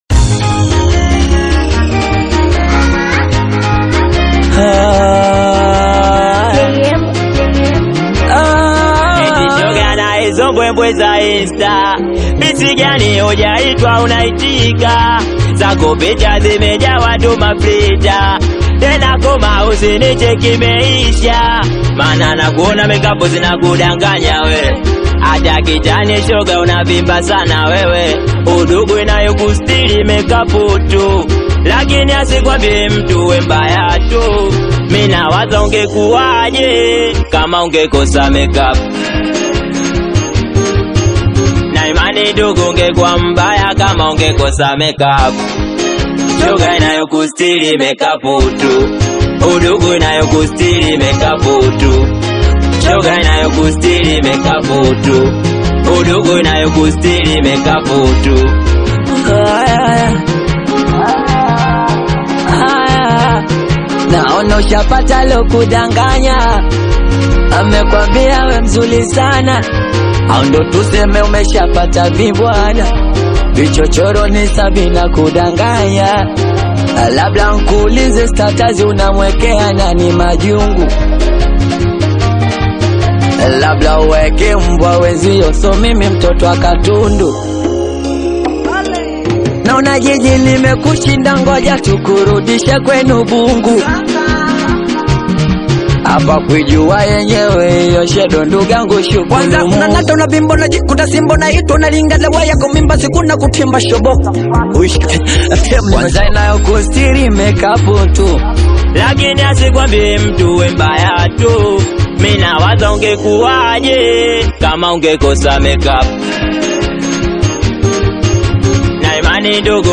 Singeli music track
Bongo Flava
Singeli song